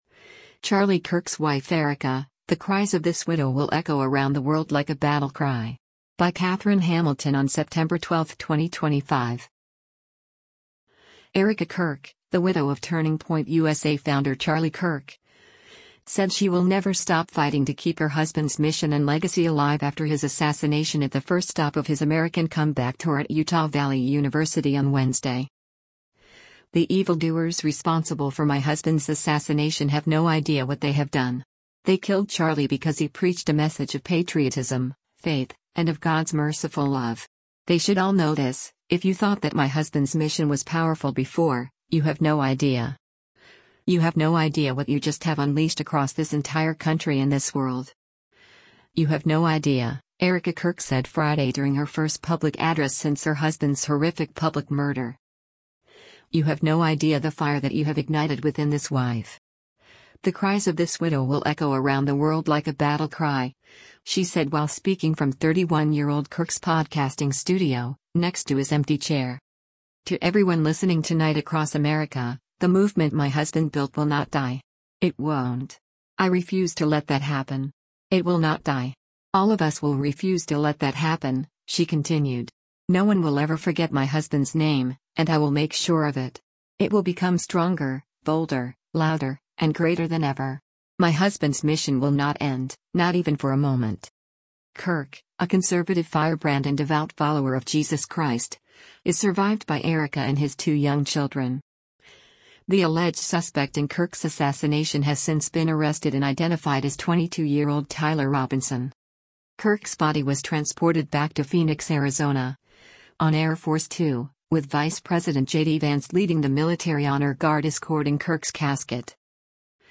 “The evildoers responsible for my husband’s assassination have no idea what they have done. They killed Charlie because he preached a message of patriotism, faith, and of God’s merciful love. They should all know this: if you thought that my husband’s mission was powerful before, you have no idea. You have no idea what you just have unleashed across this entire country and this world. You have no idea,” Erika Kirk said Friday during her first public address since her husband’s horrific public murder.
“You have no idea the fire that you have ignited within this wife. The cries of this widow will echo around the world like a battle cry,” she said while speaking from 31-year-old Kirk’s podcasting studio, next to his empty chair.